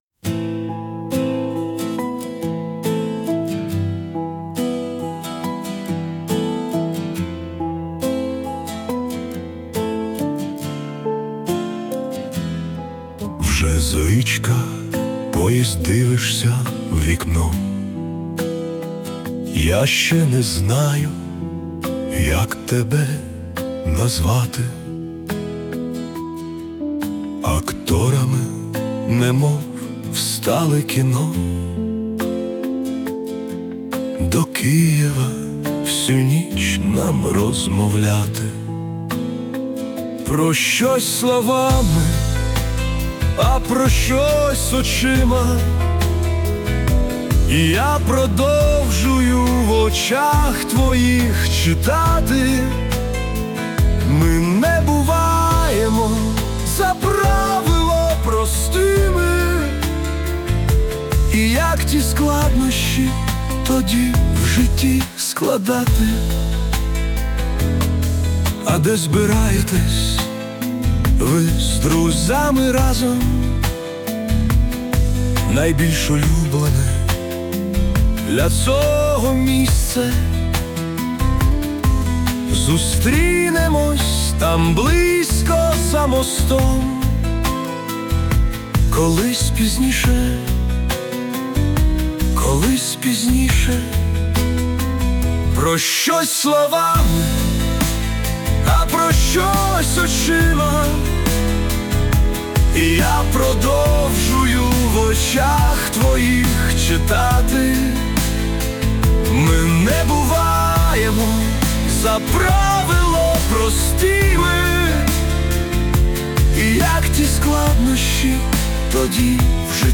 СТИЛЬОВІ ЖАНРИ: Ліричний
hi give_rose дякую ... це пісня з вірша ... у вірша було таке гарне романтичне закінчення ... а зараз вже так не бачиться hi give_rose